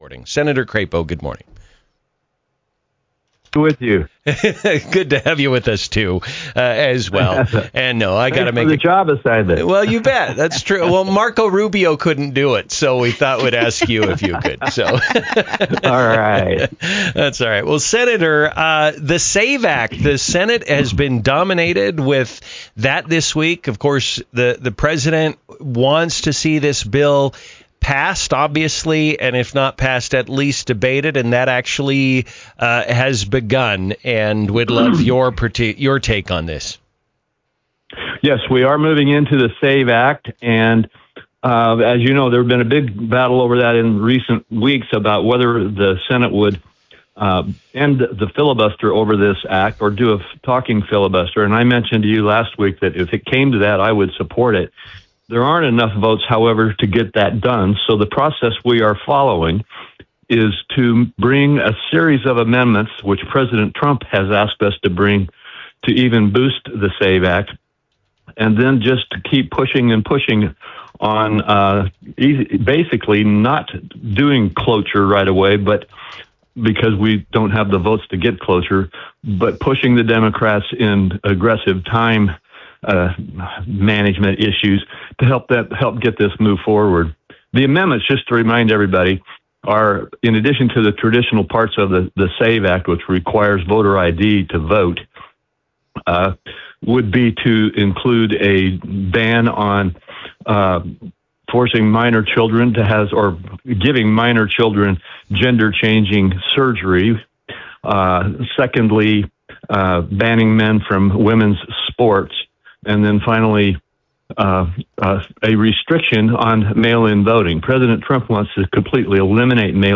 INTERVIEW: US Sen. Mike Crapo - SAVE Act. Iran - Newstalk 107.9